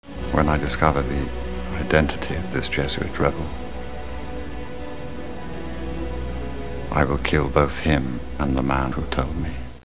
FILM QUOTES